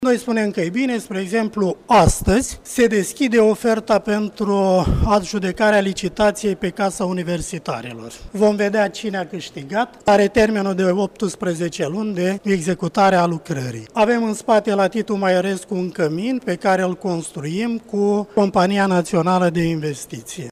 Rectorul Universităţii „Alexandru Ioan Cuza” din Iaşi, Tudorel Toader: